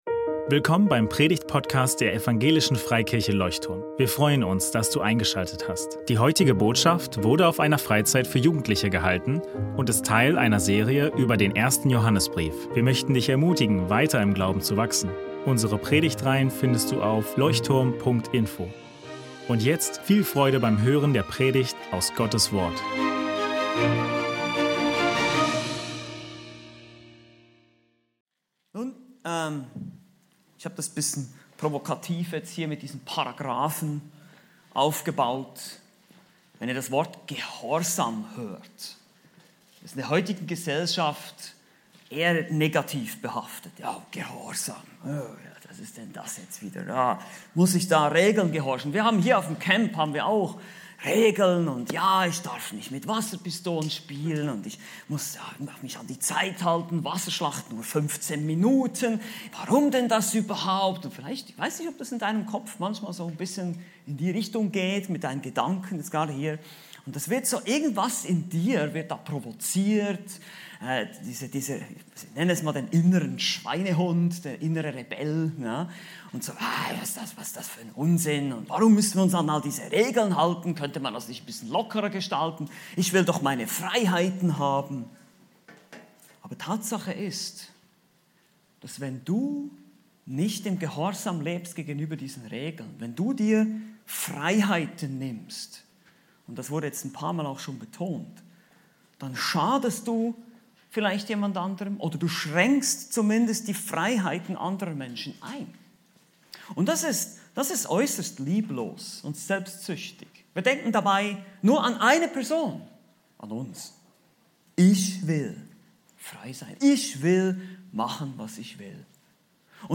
Halber Gehorsam ist ganzer Ungehorsam ~ Leuchtturm Predigtpodcast Podcast